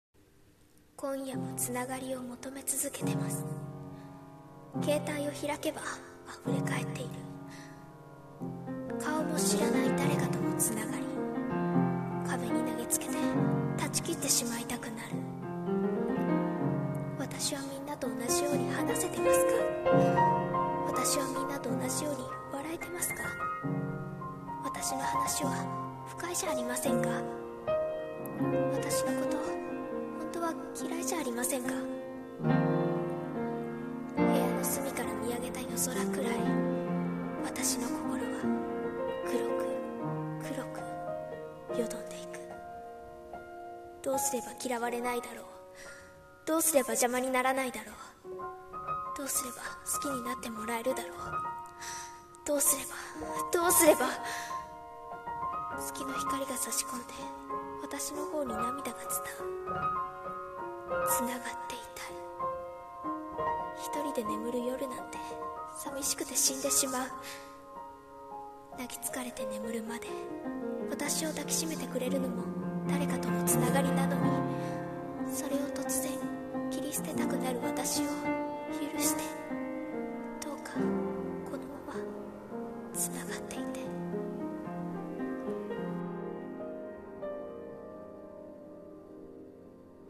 【一人声劇】つながり【台本】